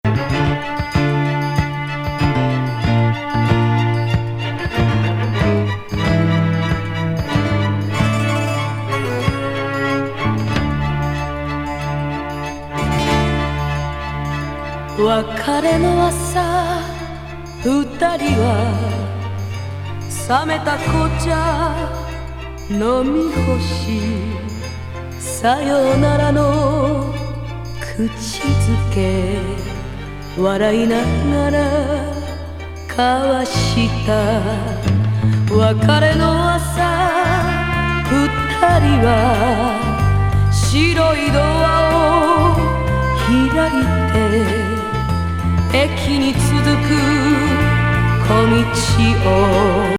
悶絶カバー曲揃い！